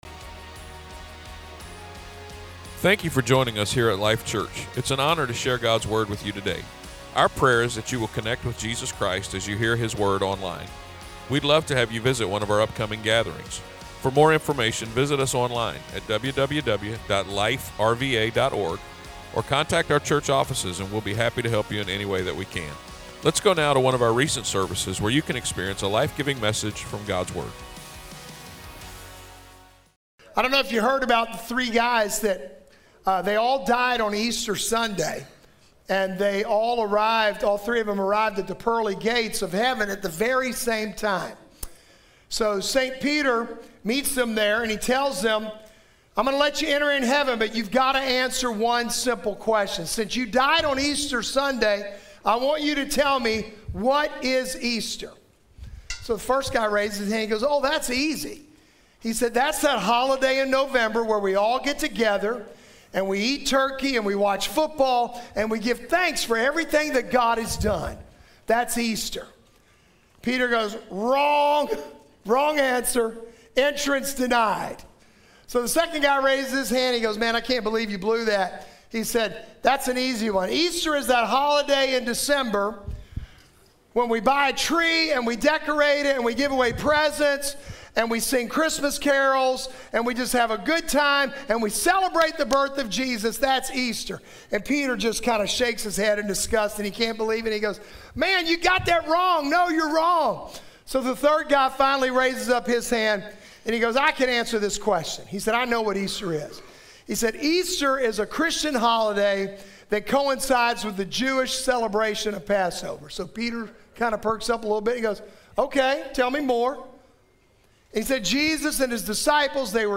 Easter Sunday was an amazing day at Life Church. Enjoy this powerful message that gives hope for a better tomorrow, with IT IS FINISHED.